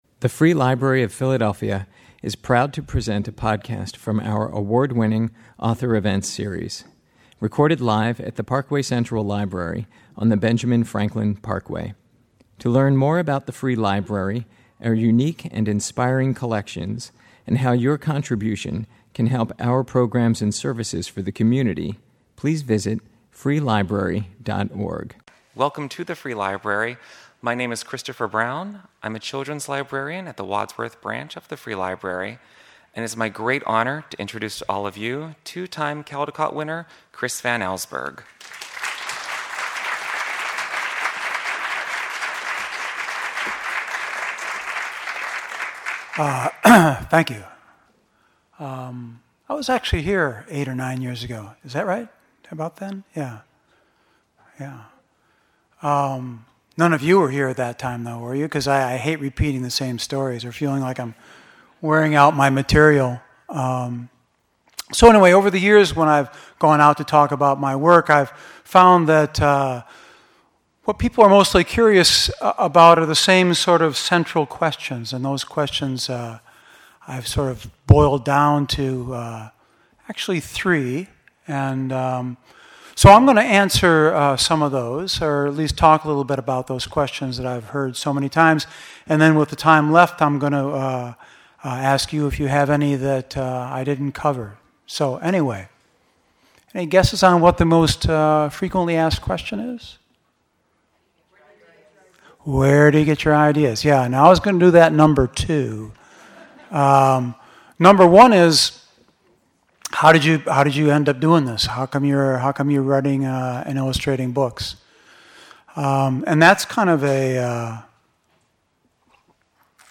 Thankfully, I did not scare him and it was a great lecture regarding his career and inspirations. Here are some pictures from the day: Here I am introducing Chris Van Allsburg in our auditorium.